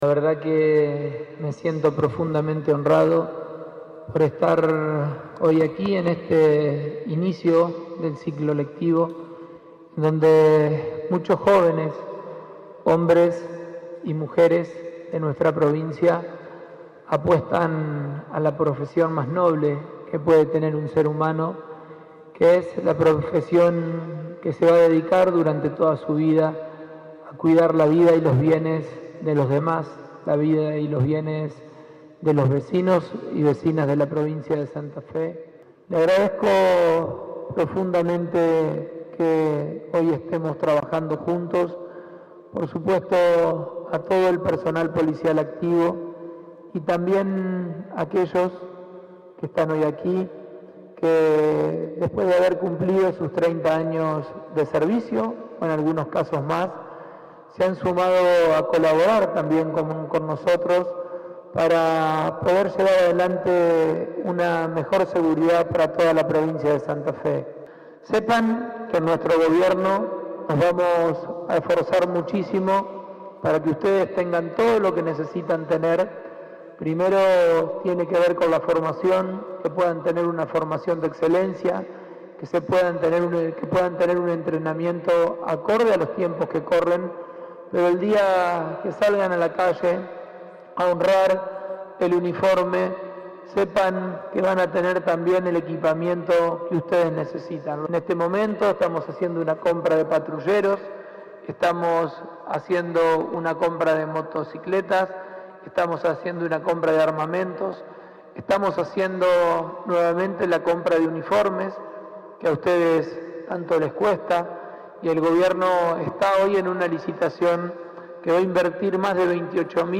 Lo afirmó el gobernador de Santa Fe durante el acto de inauguración del ciclo lectivo del Instituto de Seguridad Pública.
Pullaro inauguró el ciclo lectivo del Instituto de Seguridad Pública (ISEP) en la sede de la ciudad de Recreo.